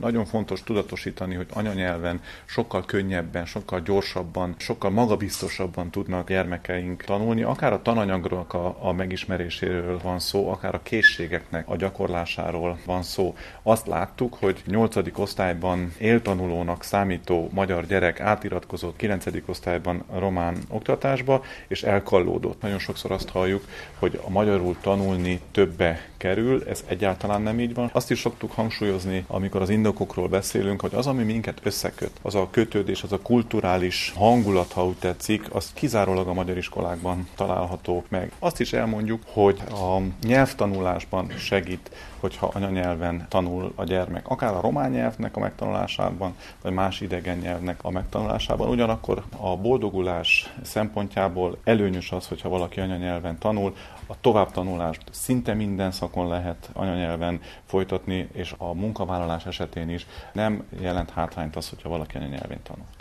A szövetség célja, hogy az új tanügyi törvény a diákok tehermentesítésére, a gyermekközpontú oktatásra fókuszáljon és teret engedjen az anyanyelvű oktatás megerősítésére is – hangzott el a sajtótájékoztatón.